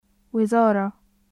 /wa/は日本語の「ワ」とよく似た音ですが，唇を丸めて突き出すようにして発音しましょう。